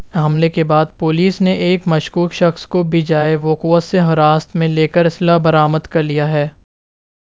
deepfake_detection_dataset_urdu / Spoofed_TTS /Speaker_08 /17.wav